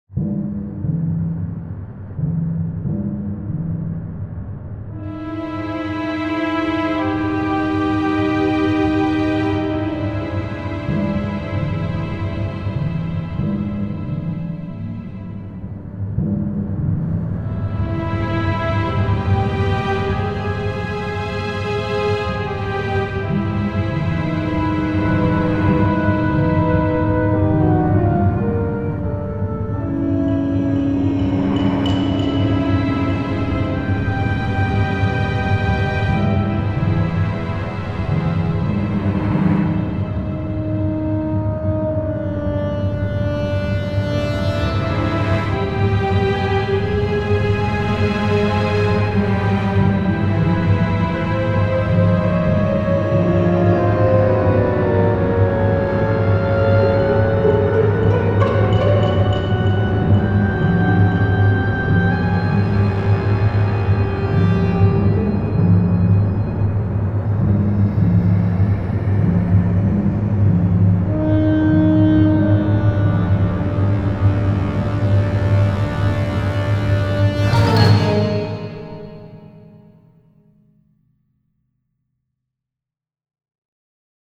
creepy suspense
Orchestral, Chamber and Cinematic